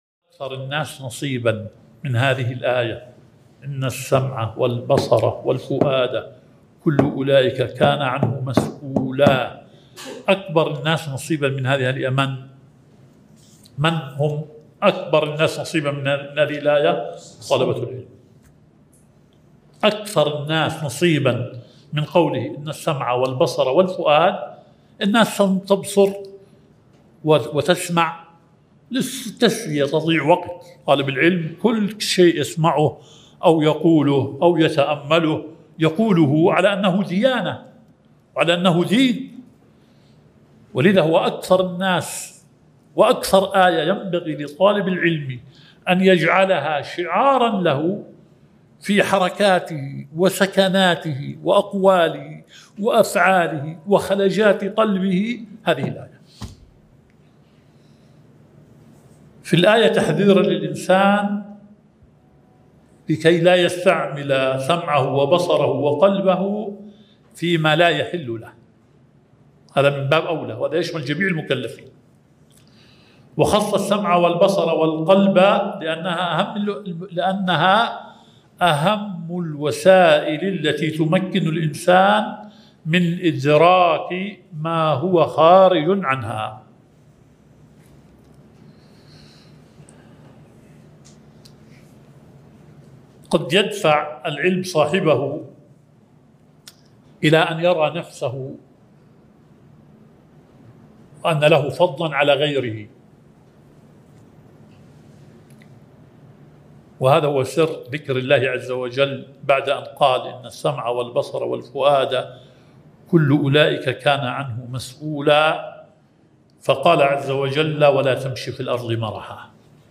المحاضرة الثانية